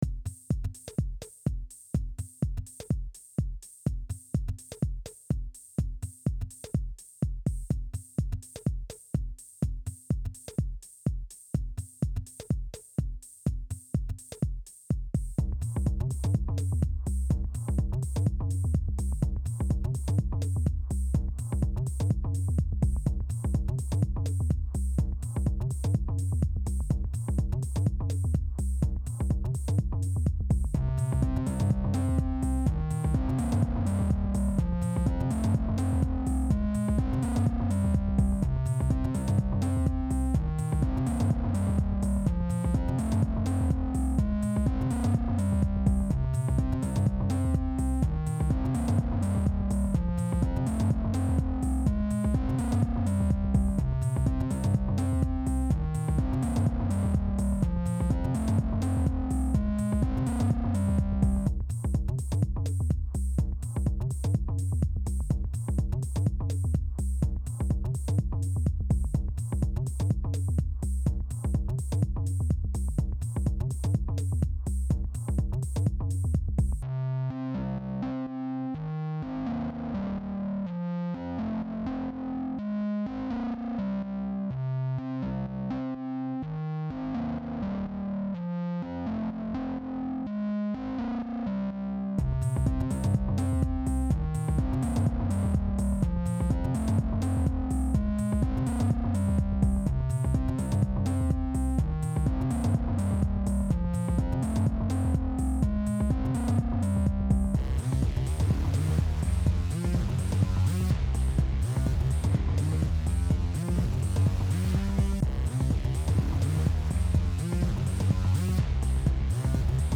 ミニマルテクノみたいな比較的単純な曲なら素材の切り貼りでなんとかなりそう。
なぜならそこはまだプリインストールされている素材だけのところだからだ。本当にダサいところは終盤ですよ。
minimal1.mp3